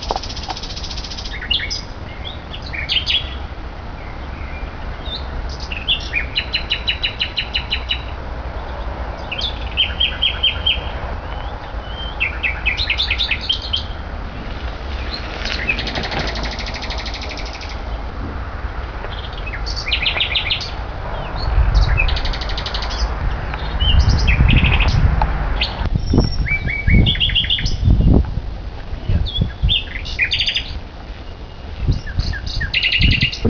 Gesang der Nachtigall
Mitten in einer Großstadt, versteckt in einem Ahorn, singt am Tage eine Nachtigall.
Nur weil der Gesang sehr charakteristisch ist, wird sie zum Schluß nach langem Suchen doch zwischen den Blättern entdeckt.